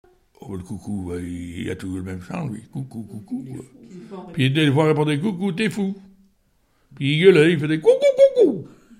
Chavagnes-en-Paillers
Genre brève
Pièce musicale inédite